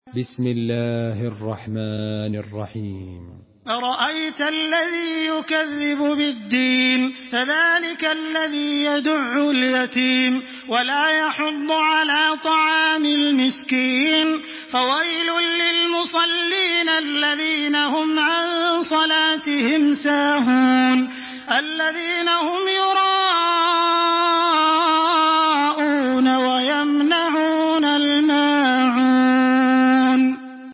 تحميل سورة الماعون بصوت تراويح الحرم المكي 1426